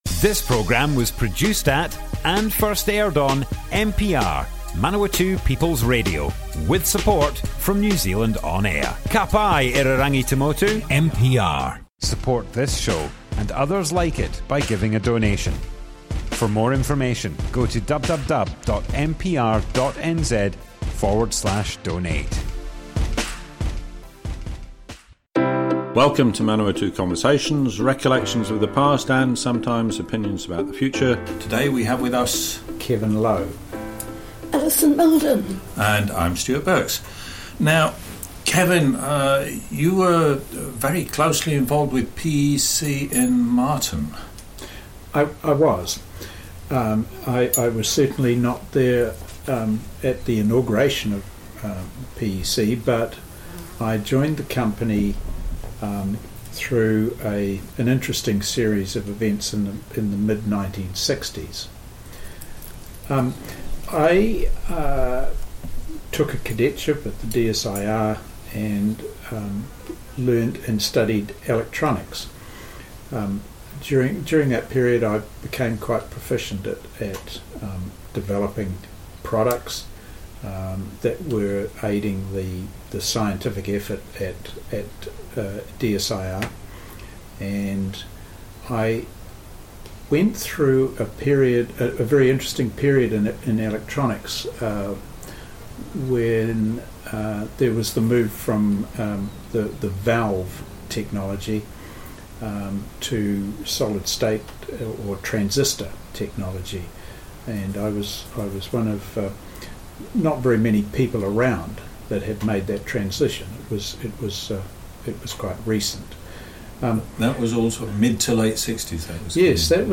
Broadcast on Manawatu People's Radio, 30th November 2021.